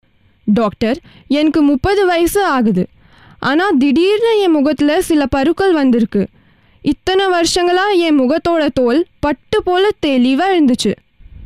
Tamil Voice Over Artists Samples for Tamil AI Voice
Tamil Voice Over Female Artist 2